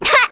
Ophelia-Vox_Attack1_kr.wav